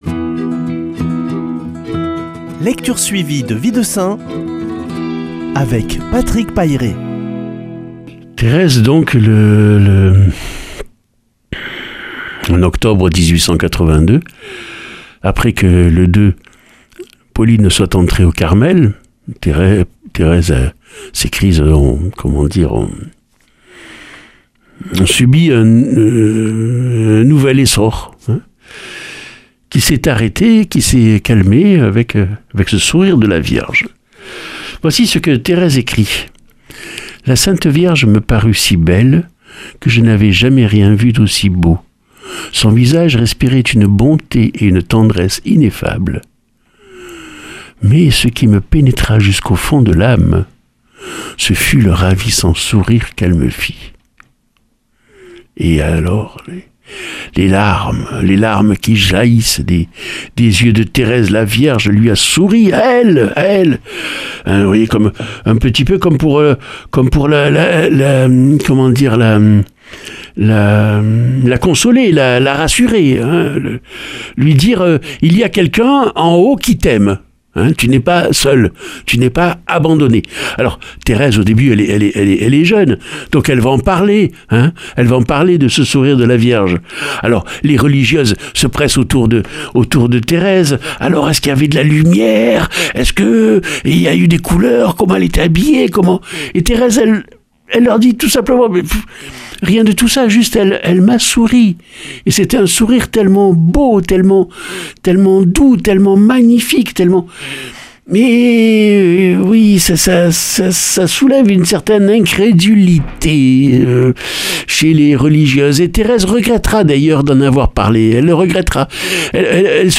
jeudi 4 février 2021 Récit de vie de saints Durée 2 min
Lecture suivie de la vie des saints